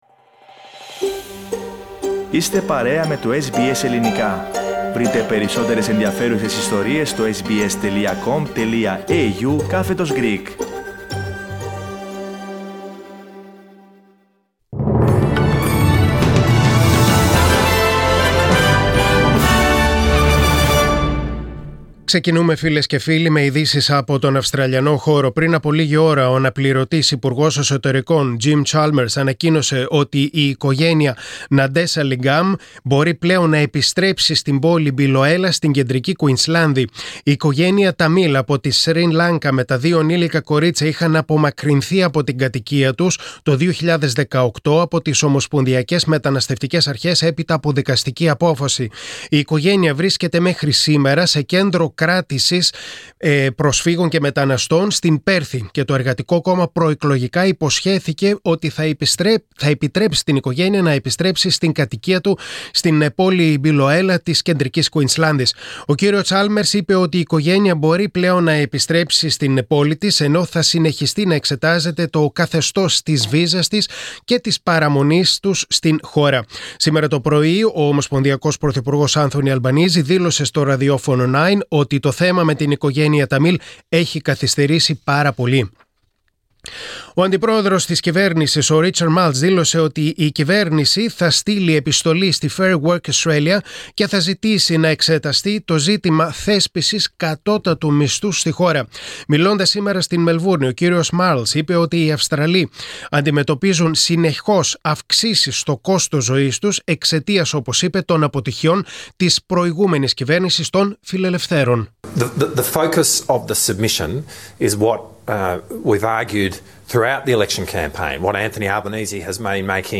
Δελτίο Ειδήσεων: Παρασκευή 27.5.2022